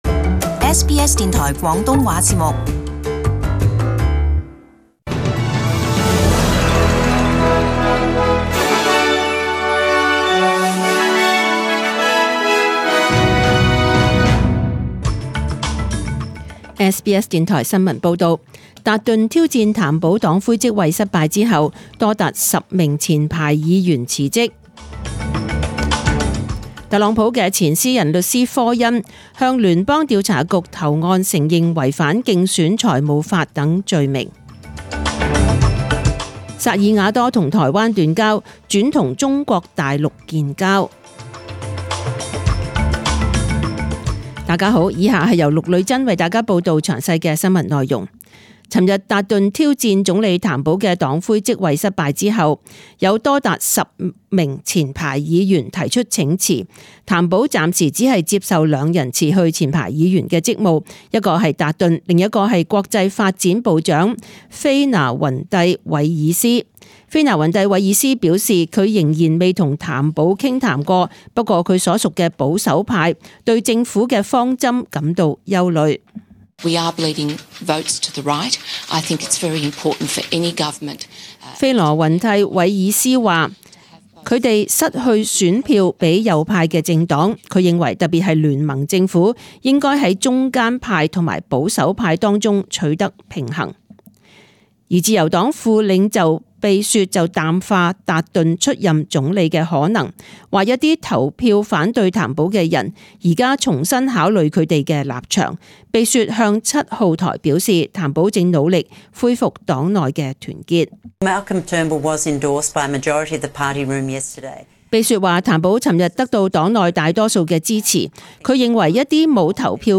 SBS中文新闻 （八月二十二日）
请收听本台为大家准备的详尽早晨新闻。